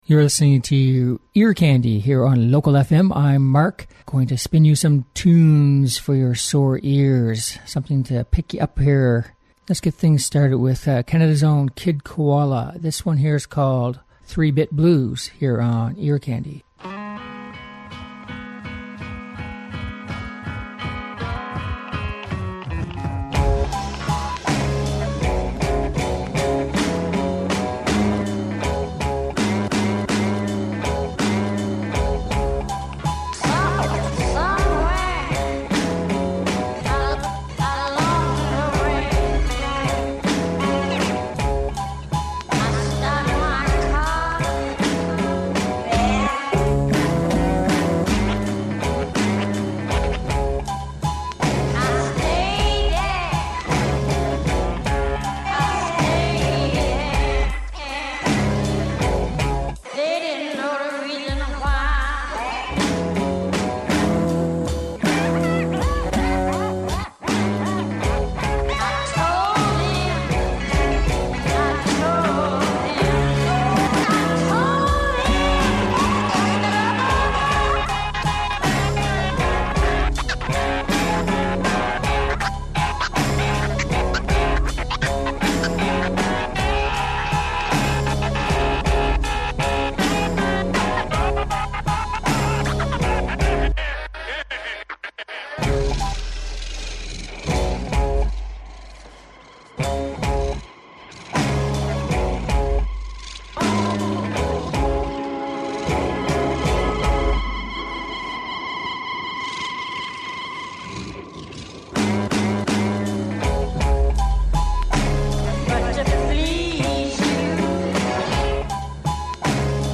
Catchy pop and rock songs with a mostly upbeat tone